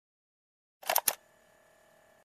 Звуки аудиокассеты
Окунитесь в атмосферу прошлого с подборкой звуков аудиокассет: характерное шипение пленки, щелчки перемотки, фоновый шум магнитофона.